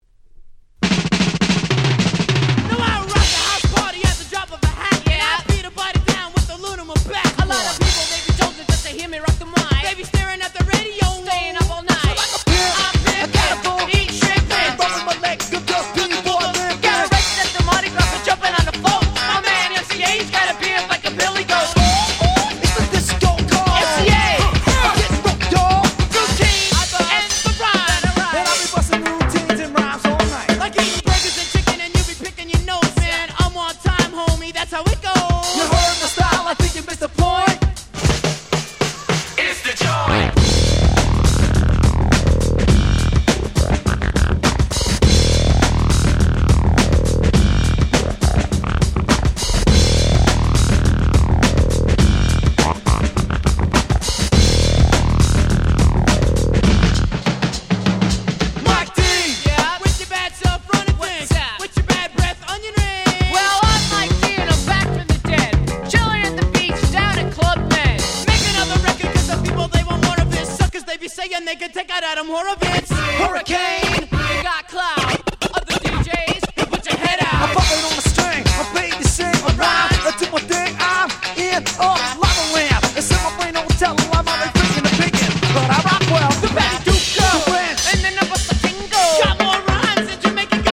89' Hip Hop Super Classics !!
こっちの98年盤の方が89年のオリジナル盤より音が良いのはナイショのお話(笑)
80's Boom Bap ブーンバップ